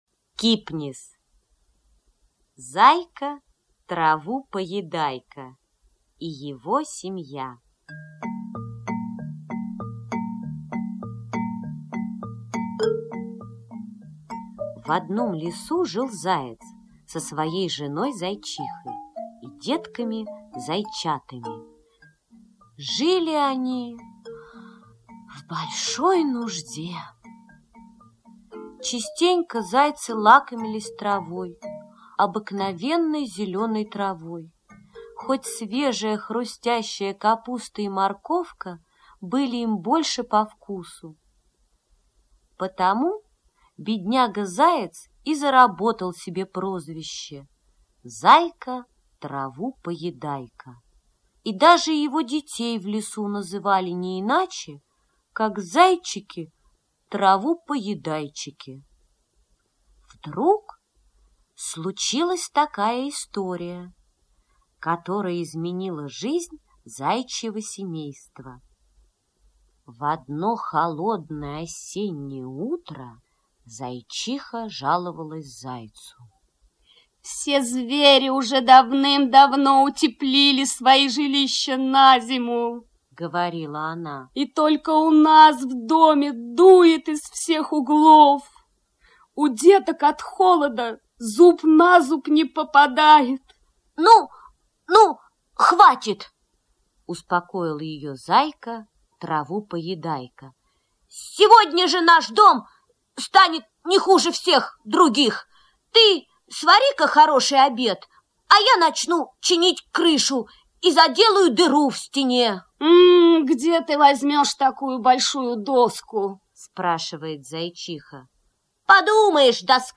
Зайка-траву поедайка и его семья - аудиосказка Кипниса - слушать онлайн